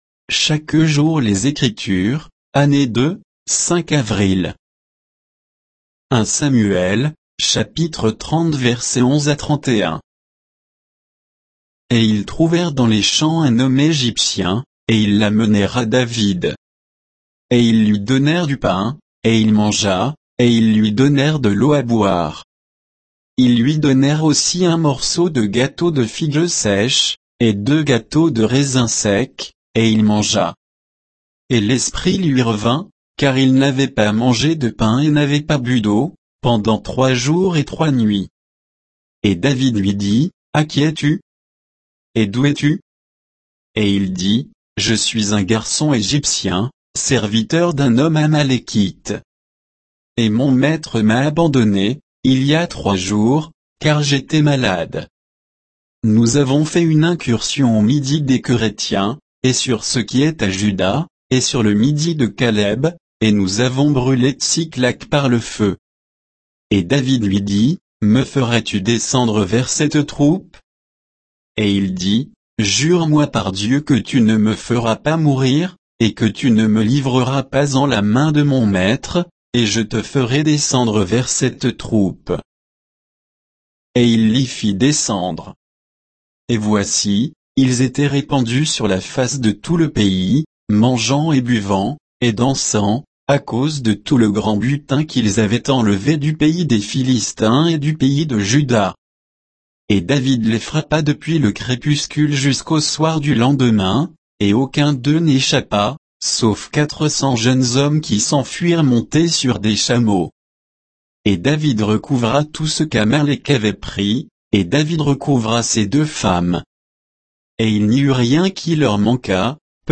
Méditation quoditienne de Chaque jour les Écritures sur 1 Samuel 30, 11 à 31